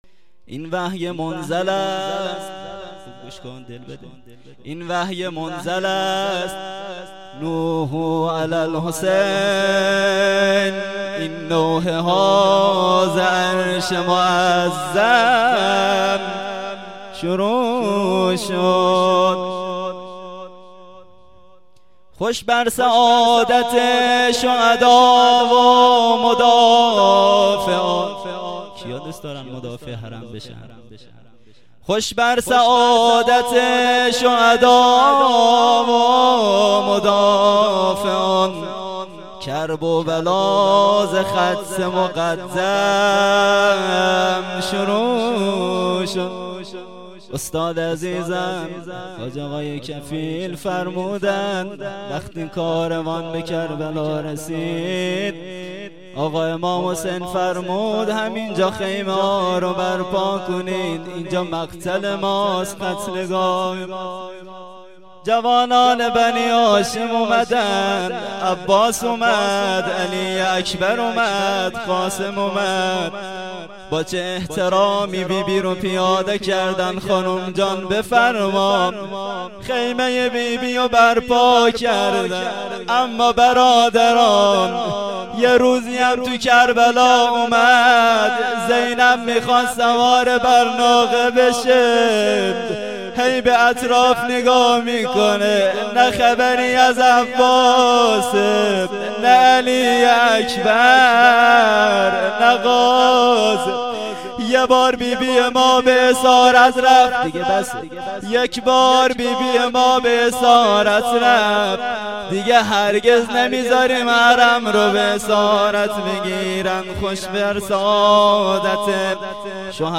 مداحی
شب دوم محرم